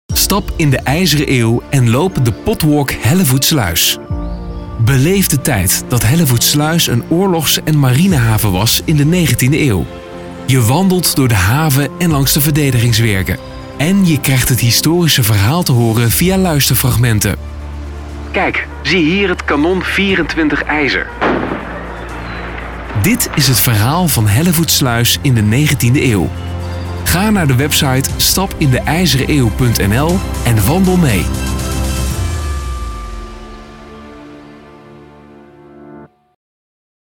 Commercial_IJzereneeuw
Commercial_IJzereneeuw.mp3